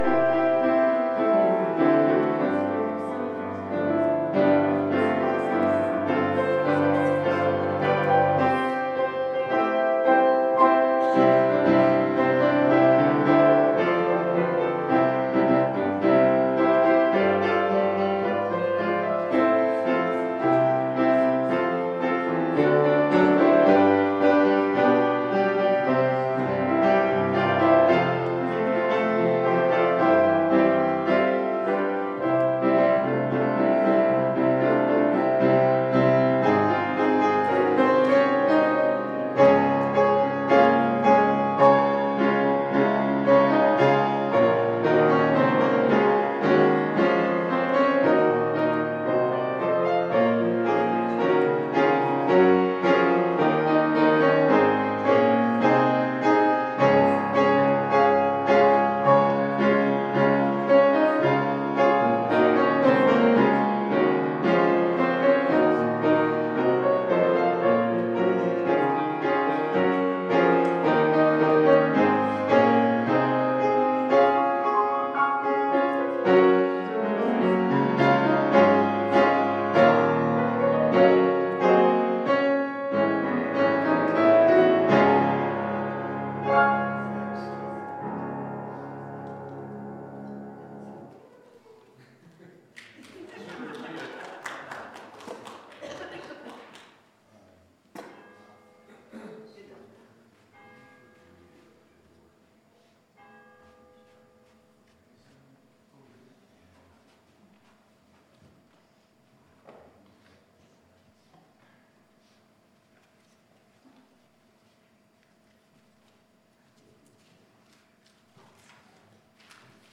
Sermons - First Lutheran Church